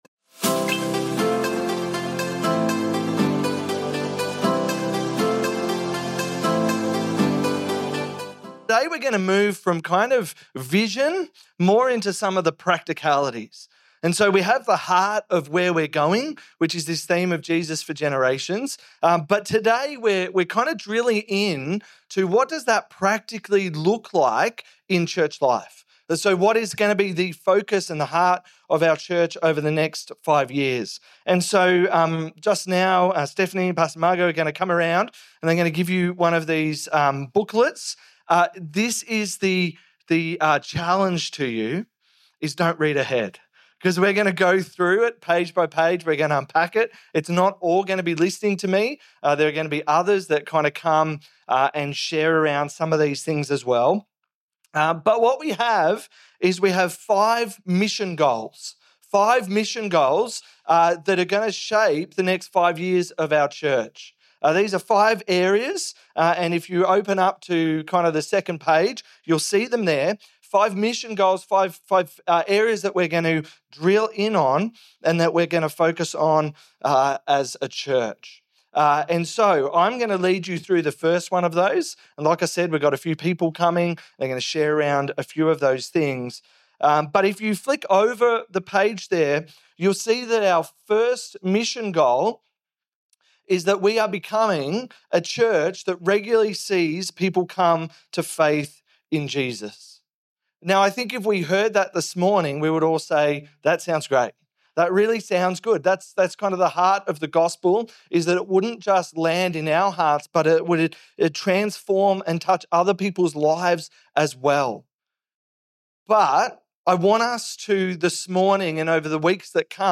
In this special service, we share our 2030 Plan and unpack what it means to live out our vision: Jesus for Generations. You'll hear from members of our team as they break down the big picture and the practical steps we're taking together to build a legacy of faith.